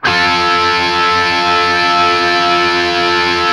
TRIAD F  L-L.wav